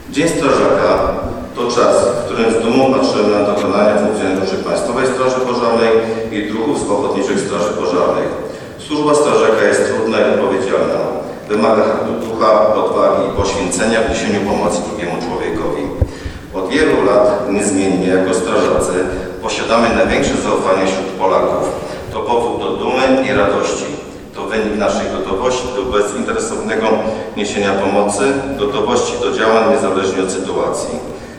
W piątek (23.05) w Tarnowie odbyły się powiatowe obchody Dnia Strażaka.